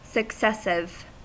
Ääntäminen
Synonyymit consecutive Ääntäminen US Haettu sana löytyi näillä lähdekielillä: englanti Käännös Adjektiivit 1. perättäinen 2. peräkkäinen Määritelmät Adjektiivit Coming one after the other in a series .